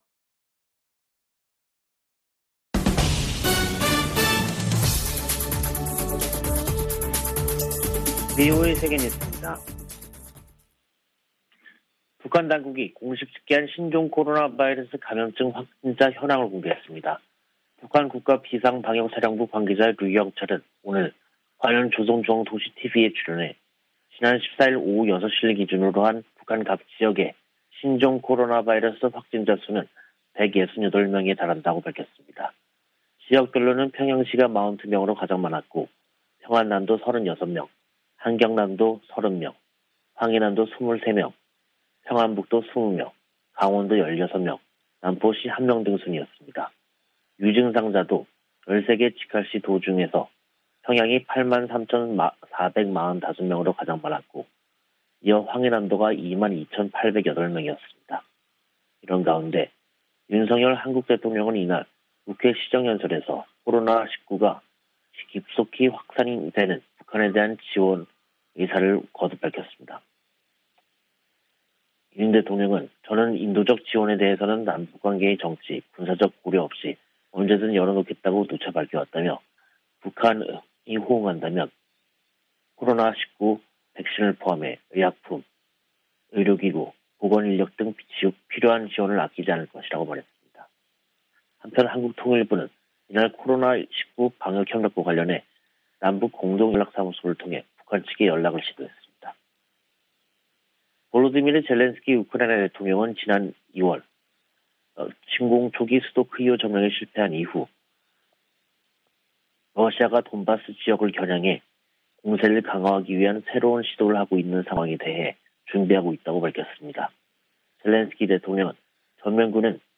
VOA 한국어 간판 뉴스 프로그램 '뉴스 투데이', 2022년 5월 16일 2부 방송입니다. 북한에서 신종 코로나바이러스 감염병이 폭증한 가운데, 윤석열 한국 대통령은 백신 등 방역 지원을 아끼지 않겠다고 밝혔습니다. 미 국무부는 한국 정부의 대북 백신 지원 방침에 지지 입장을 밝히고, 북한이 국제사회와 협력해 긴급 백신 접종을 실시할 것을 촉구했습니다. 미국과 아세안은 특별정상회의에서 채택한 공동 비전성명에서 한반도의 완전한 비핵화 목표를 확인했습니다.